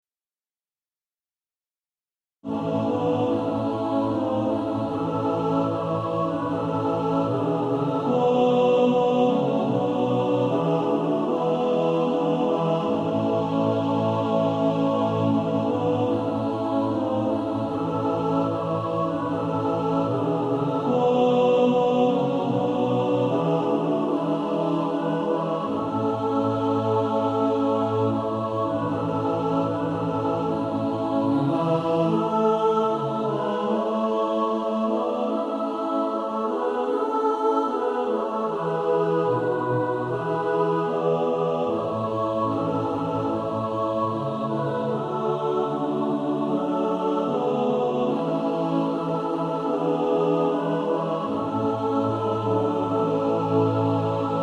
(SATB) Author
Practice then with the Chord quietly in the background.